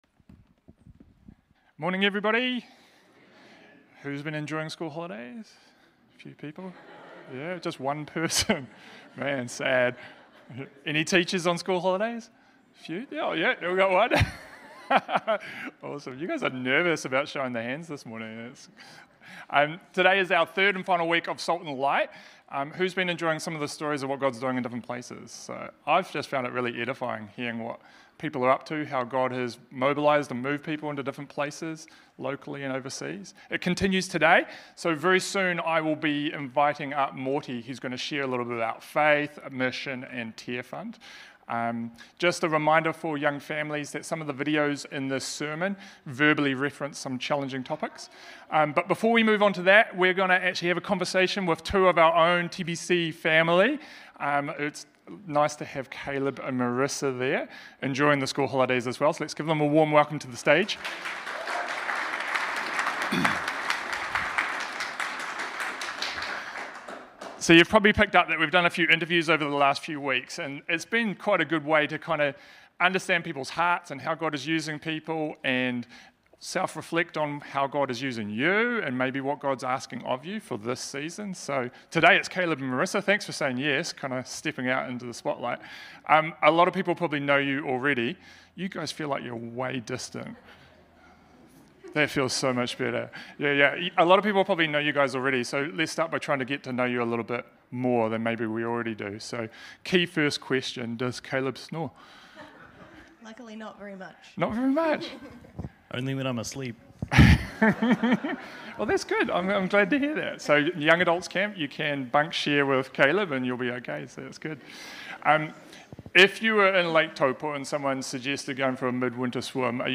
Sermons | Titirangi Baptist Church
Guest Speaker